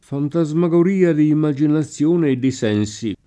fantasmagoria [ fanta @ ma g or & a ] s. f.